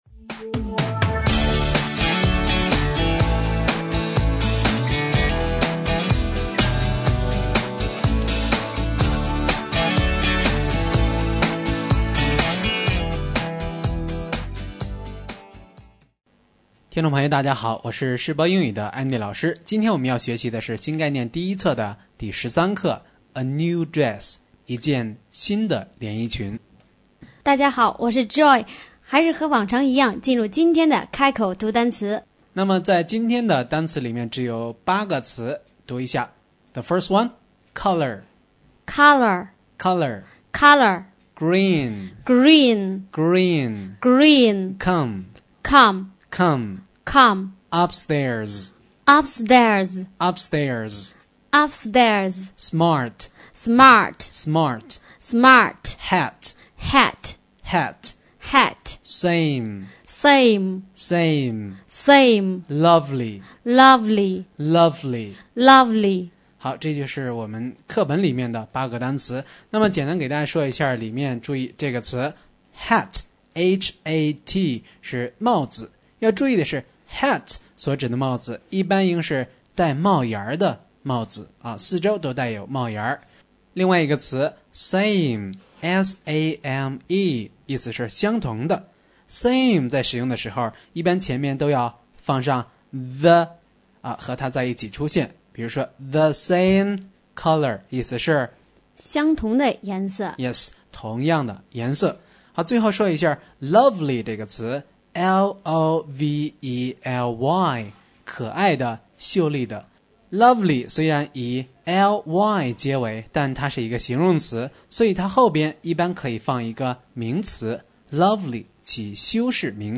新概念英语第一册第13课【开口读单词】